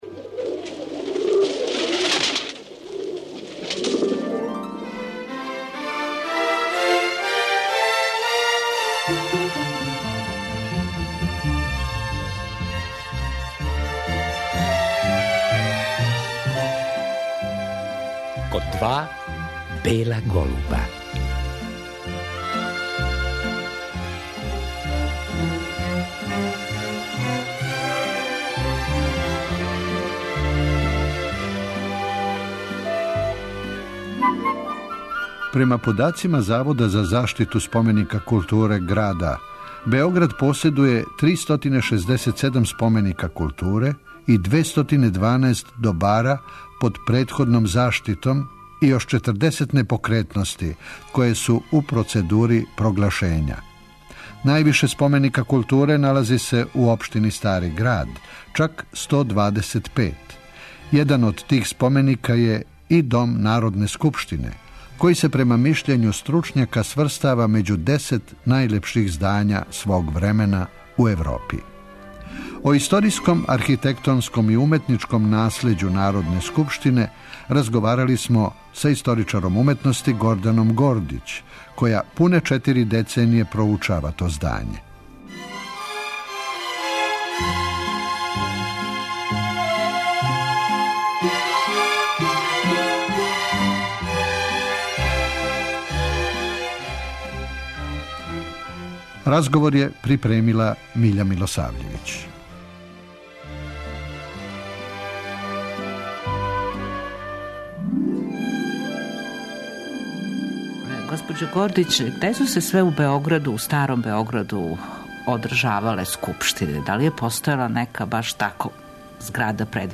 О историјском, архитектонском и уметничком наслеђу здања разговали смо са историчарем уметности